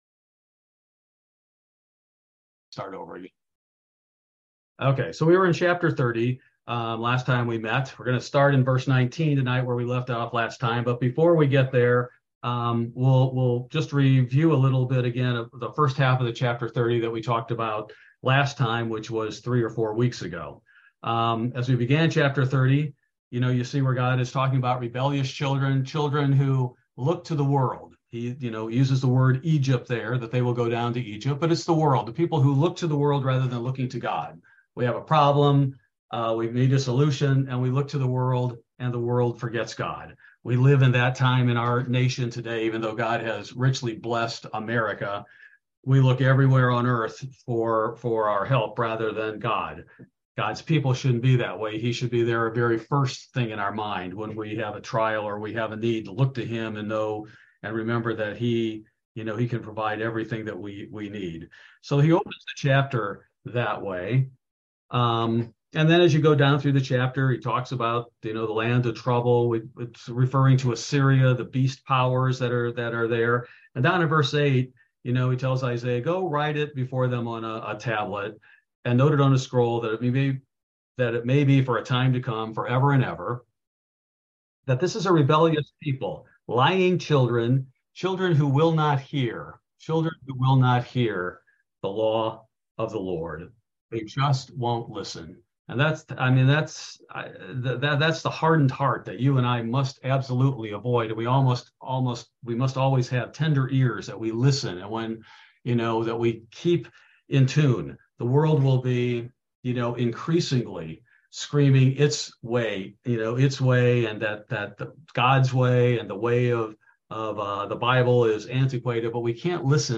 Bible Study: April 26, 2023